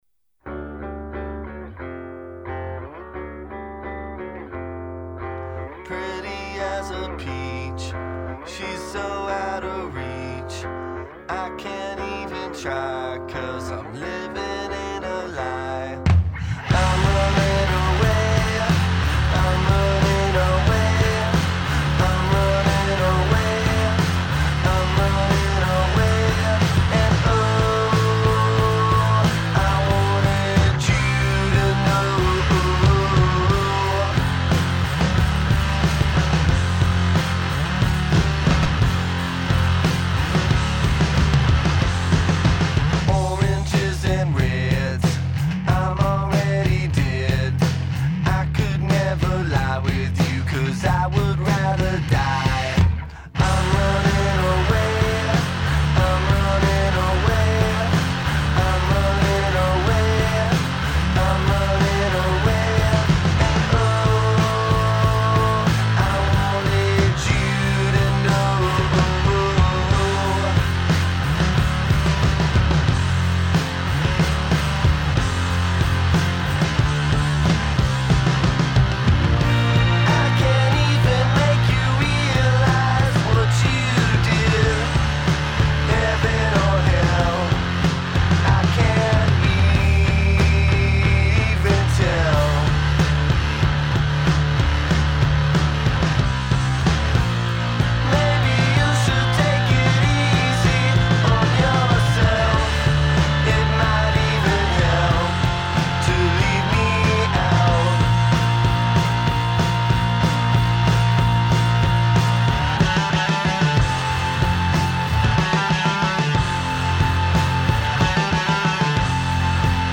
Oh.Ma.Gawd…. another post about fuzz rock band.
Fuzzy, poppy…
Its got the hooks and simple sing-song rhymes.